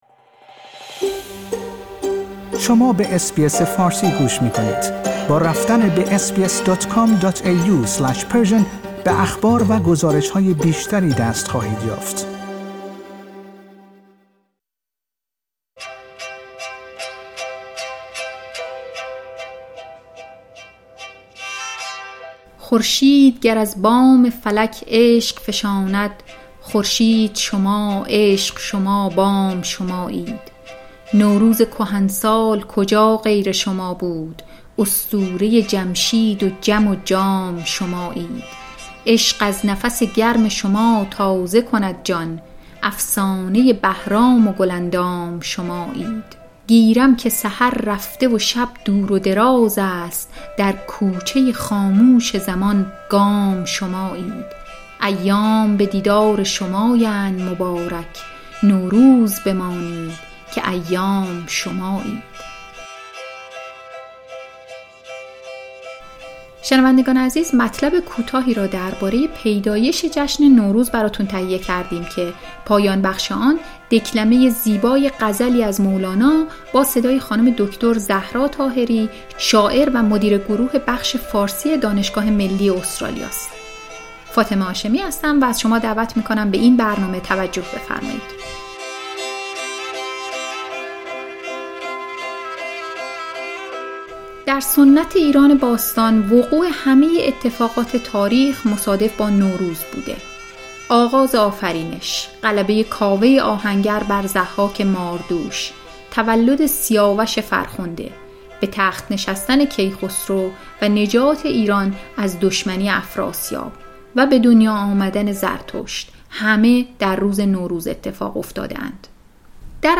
مطلب کوتاهی را درباره پیدایش جشن نوروز برایتان تهیه کرده‌ایم که پایان بخش آن، دکلمه زیبای غزلی از مولانا